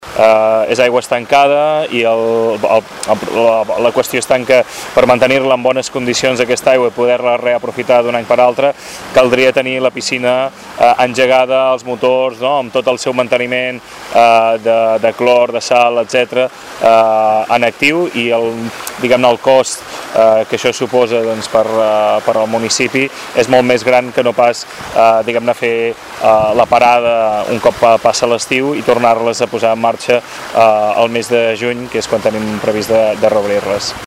Les piscines municipals s’omplen cada any de nou. Una opció que fa anys que es va adoptar, si bé mantenir-la durant tot l’any suposava un cost econòmic inassolible per les arques municipals i els ciutadans, com explica el regidor.